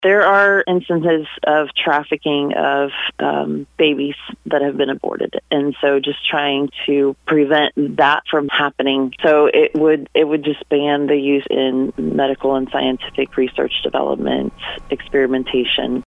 Laubinger was our guest on the weekly KFMO Legislative Report heard every Friday morning during the Missouri Legislative session on AM 1240, KFMO.